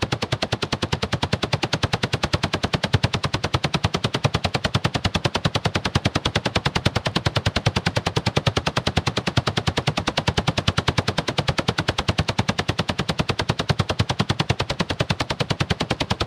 Helicopter.wav